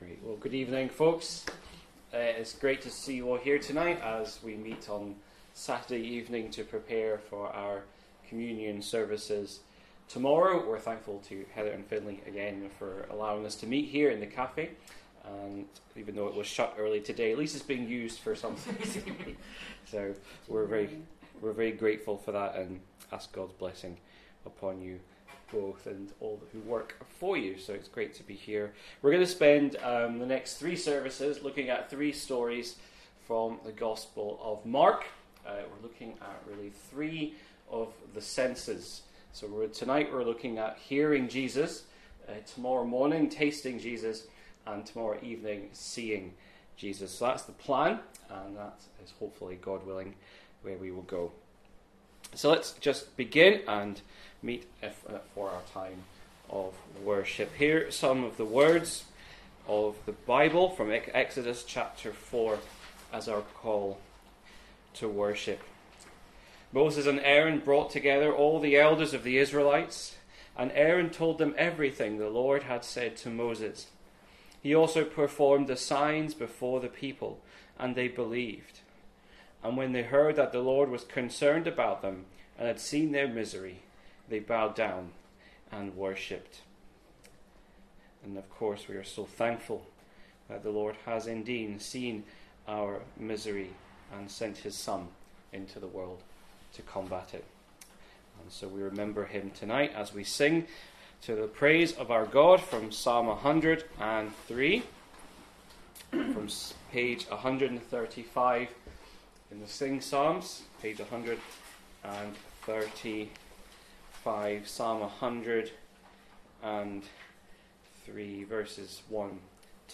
Saturday-Service.mp3